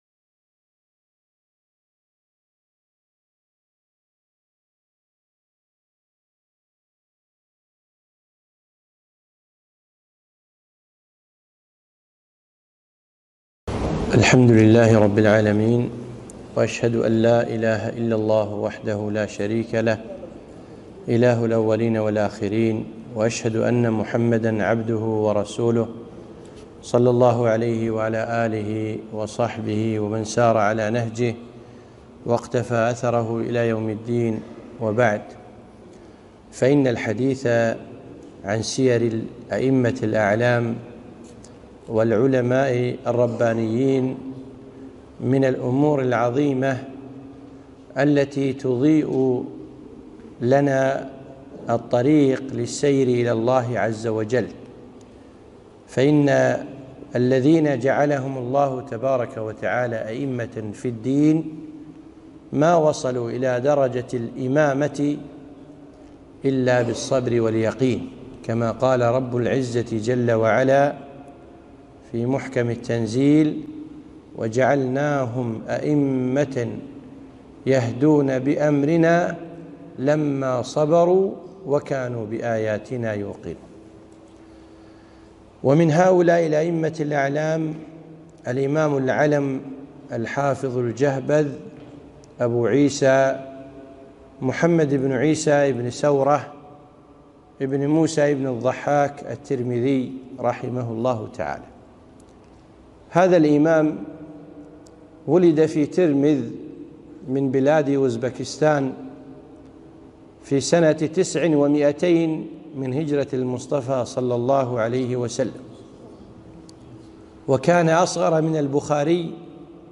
محاضرة - وقفات مع سيرة الإمام الترمذي - رحمه الله